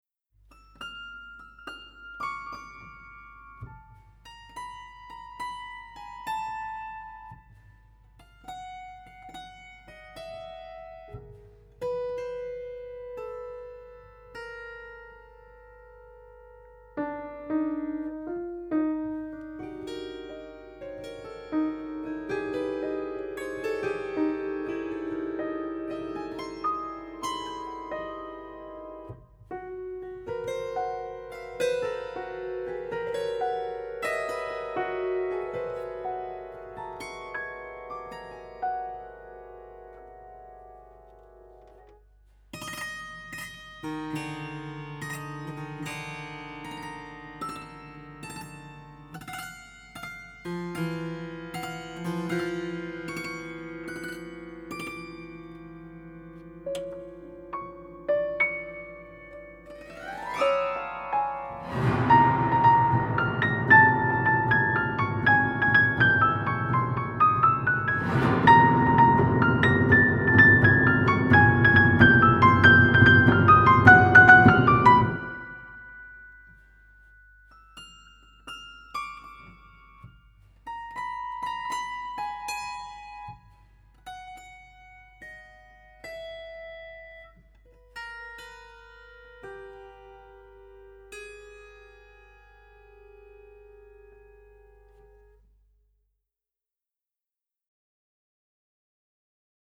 per pianoforte